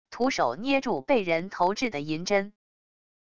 徒手捏住被人投掷的银针wav音频